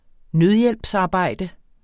Udtale [ ˈnøðjεlbs- ]